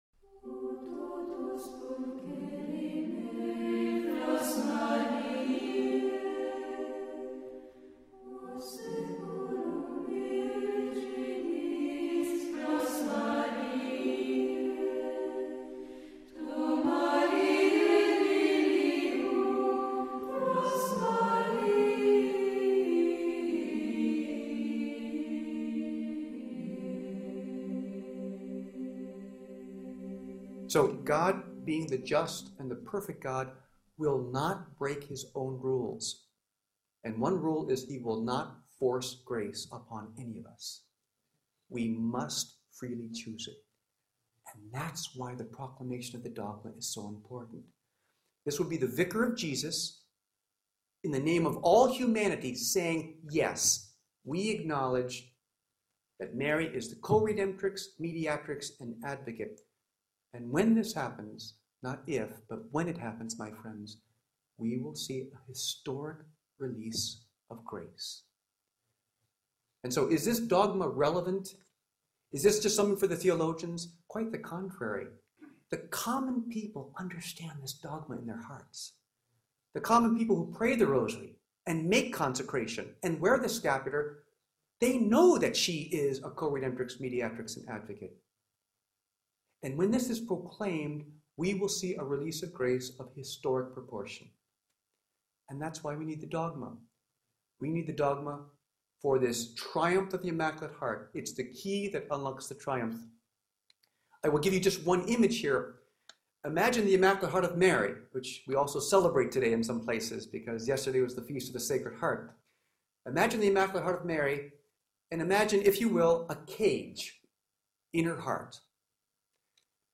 A major Marian conference discussing the impact the proclamation of the Coredemption Dogma would have on the Church for the triumph of the Immaculate Heart of Mary in the context of approved Marian Apparitions. This conference was held on the anniversary of the last apparition of Our Lady of All Nations on May 31, 1959, the feast of the Visitation in Amsterdam, Netherlands where the apparitions took place along side one of the famous Dutch canals at the historic Victoria Hotel in Park Plaza at the heart of the city in the presence of 100 select guests.